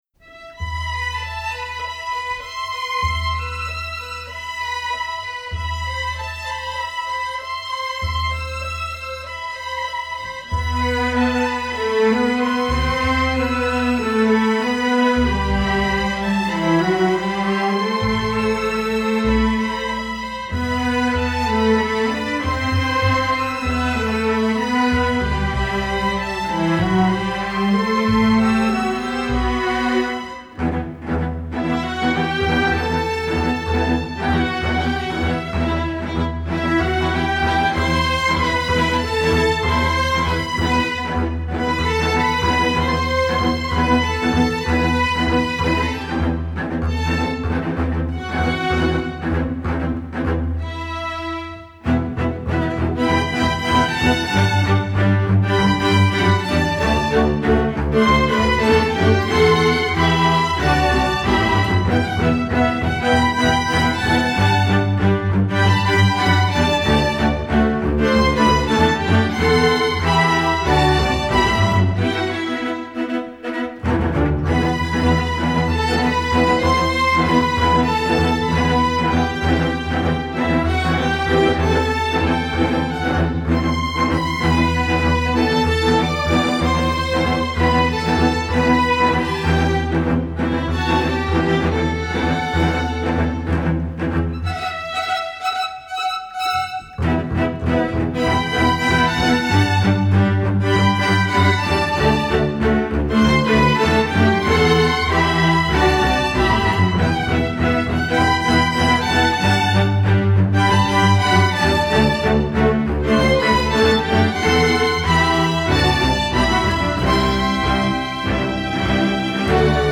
Instrumentation: string orchestra
pop, rock